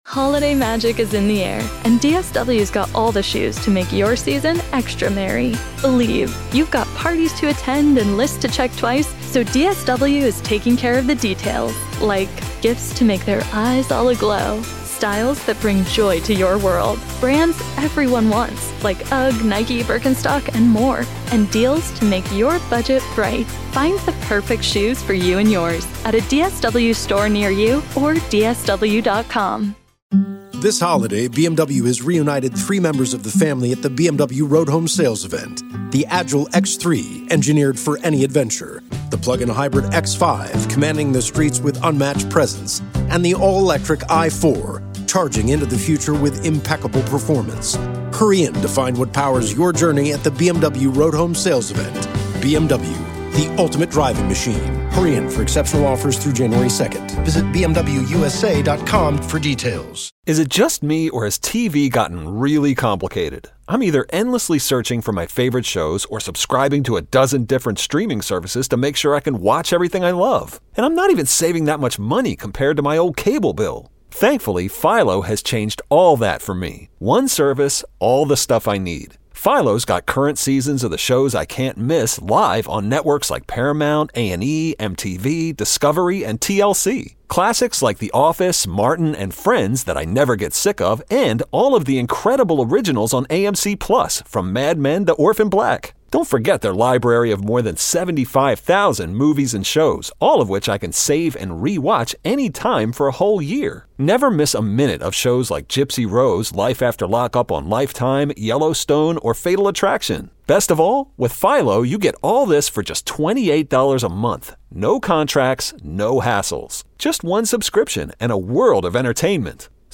The guys also got caught in laughter as Bill Belichick is introduced as the UNC head coach.